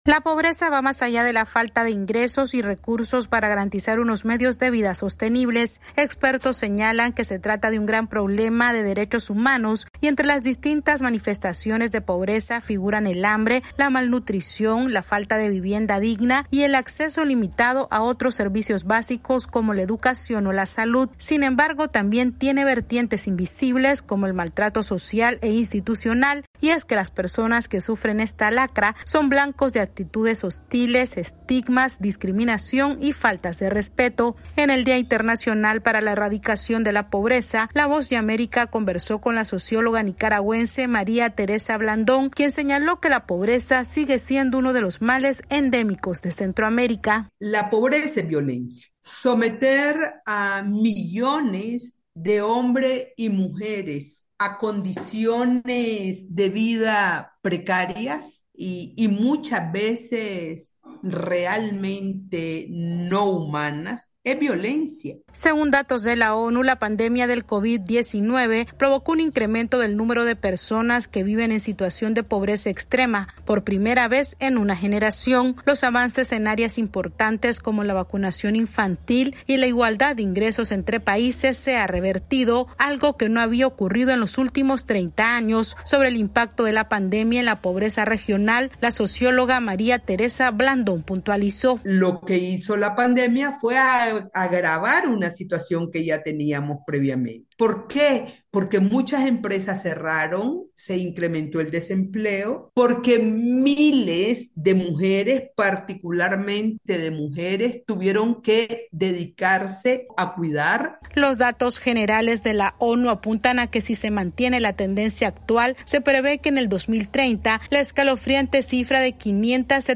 En el día Internacional para la Erradicación de la Pobreza, Centroamérica continúa siendo una de las regiones más desiguales del mundo debido a la inacción de los Gobiernos, la falta de inversión en educación y los conflictos sociopolíticos. Esta es una actualización de nuestra Sala de Redacción.